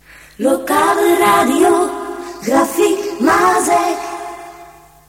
Gezongen jingle a-capella